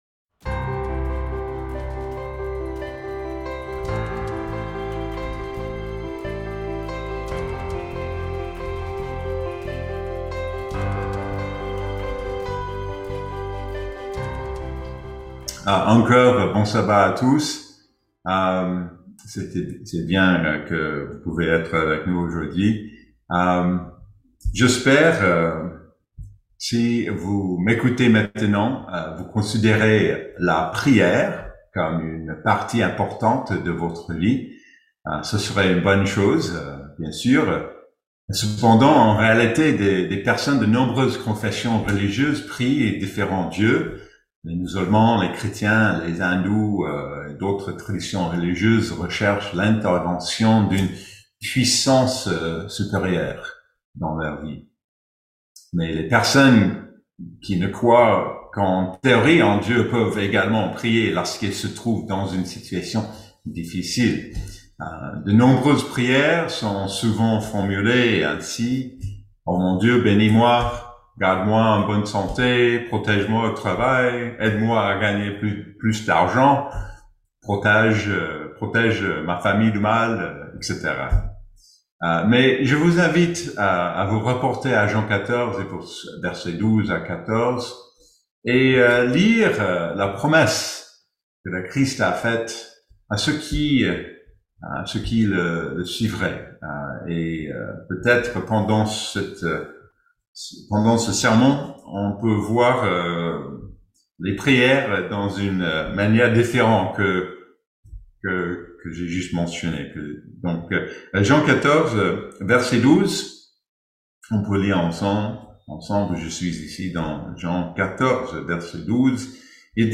Dans ce sermon